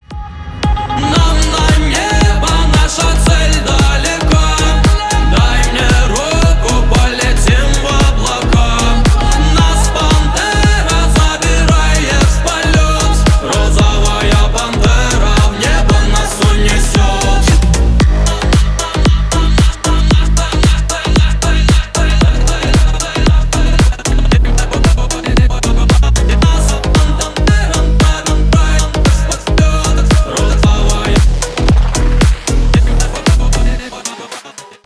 громкие , поп